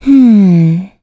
Gremory-Vox_Think_a.wav